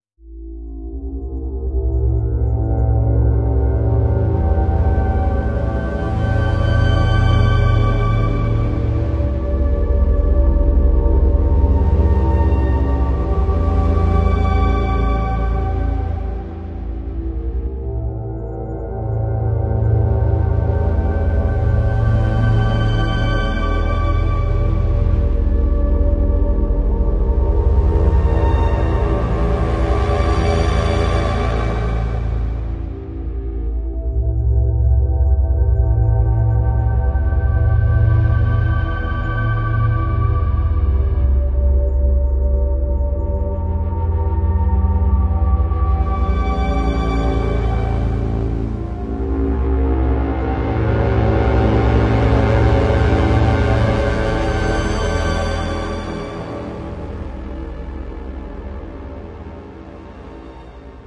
合成器声音 " EHSOUNDGEN
描述：大量的即兴合成噪音混乱嘶嘶声、隆隆声、奇怪的声音、失真，以及科幻或太空般的声音.
标签： 嘶嘶声 噪声 隆隆声 合成器 电子
声道立体声